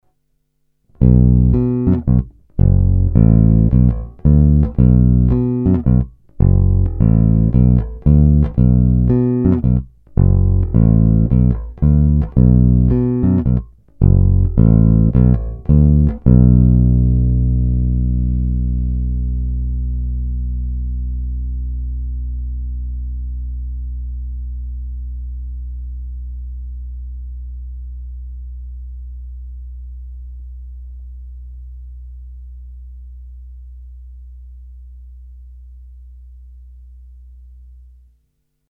Není-li uvedeno jinak, následující nahrávky byly provedeny rovnou do zvukové karty a dále kromě normalizace ponechány v původním stavu.
Hra nad snímačem